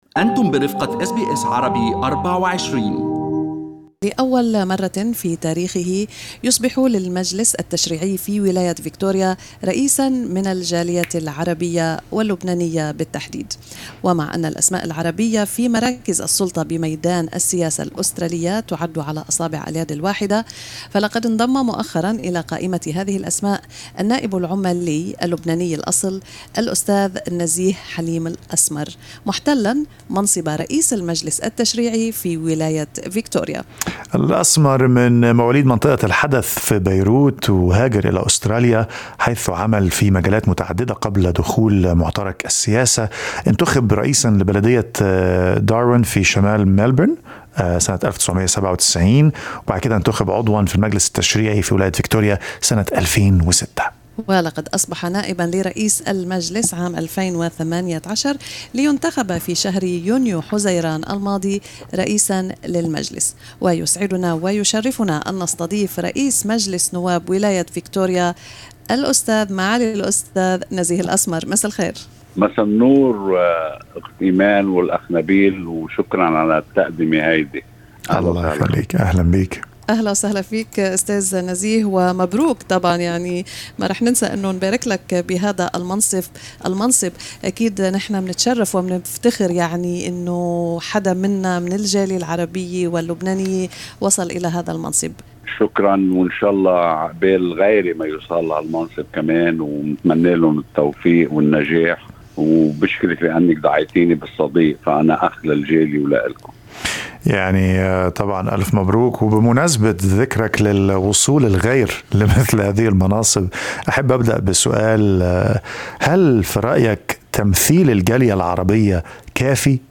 وفي حديث مع SBS Arabic24 سلط الأسمر الضوء على التحديات والصعوبات التي يواجهها المنخرطون في المعترك السياسي لكنه دعا الشباب من أبناء المهاجرين على اجتياز "الجسر السياسي" الذي بناه من سبقهم من أبناء الجالية العربية.